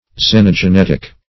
Search Result for " xenogenetic" : The Collaborative International Dictionary of English v.0.48: Xenogenetic \Xen`o*ge*net"ic\, a. (Biol.)
xenogenetic.mp3